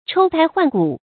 抽胎換骨 注音： ㄔㄡ ㄊㄞ ㄏㄨㄢˋ ㄍㄨˇ 讀音讀法： 意思解釋： 見「脫胎換骨」。